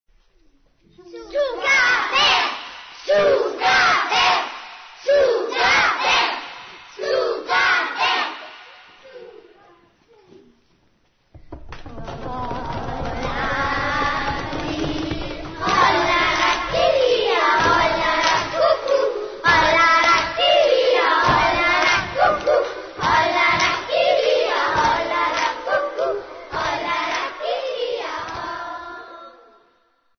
Beim Sommerfest 2011 sangen die Klassen 2a und 2c zusammen ihr selbstgedichtetes Schnaderhüpferl “Wannst in Schui sogt a willst kema”.
Und hier noch eine kleine Zugabe der Klassen 2a und 2c (2011).